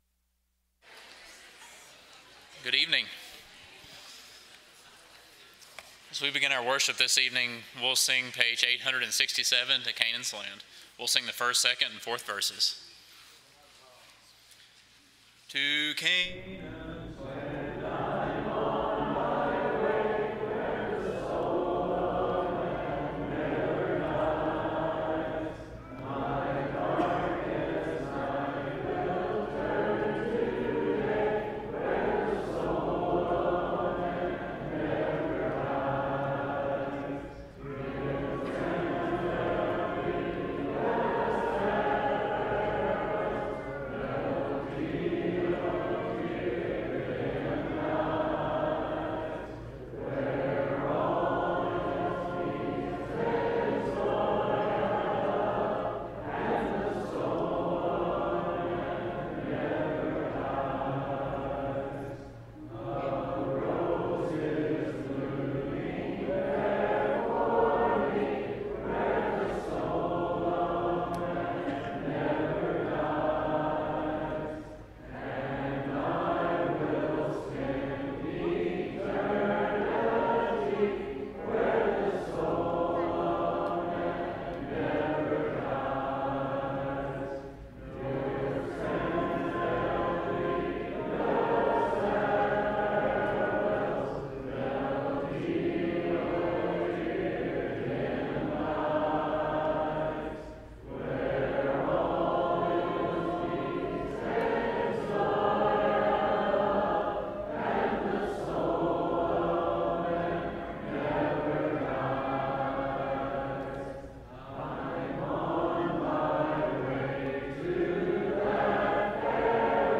Series: Sunday PM Service